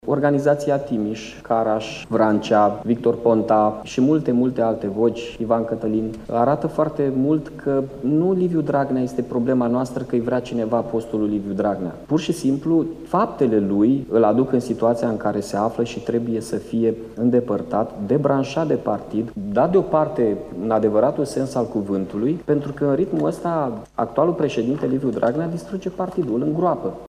Primarul Iaşului, Miha Chirica, a solicitat astăzi, într-o conferinţă de presă, un congres extraordinar al Partidului Social Democrat.